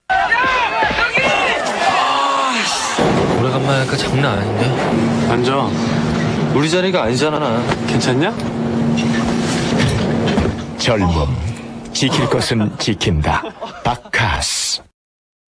でも現地テレビCM(付録C参照)などでは、「バッカ〜ス」とのばして発音しているので、
韓国で6月頃放送されていたCM。
そういうノリではないです。静かで対照的。